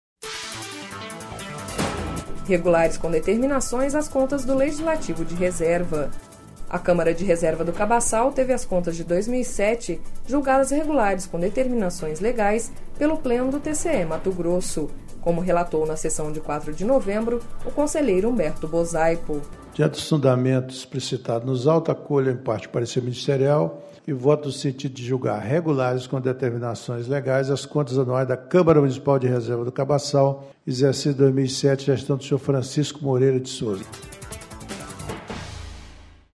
A Câmara de Reserva do Cabaçal teve às contas de 2007 julgadas regulares com determinações legais pelo Pleno do TCE-MT./ Como relatou na sessão de 04 de novembro o conselheiro Humberto Bosaipo.// Sonora: Humberto Bosaipo – conselheiro do TCE-MT